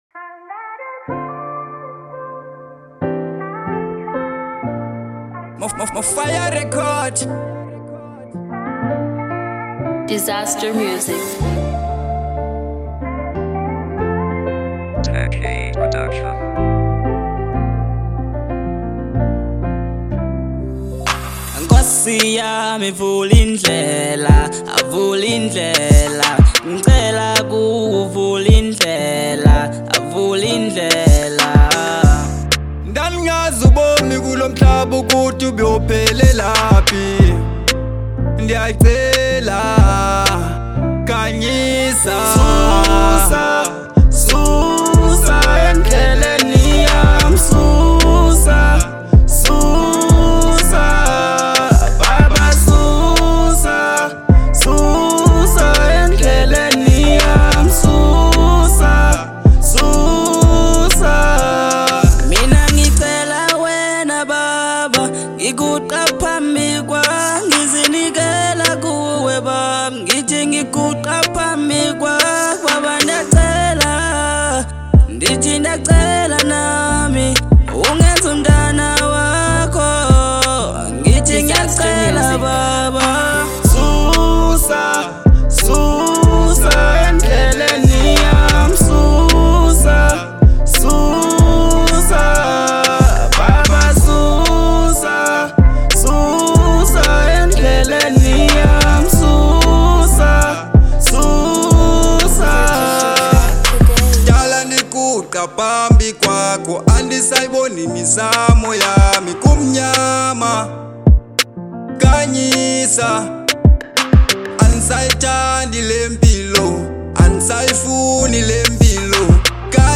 02:46 Genre : Afro Pop Size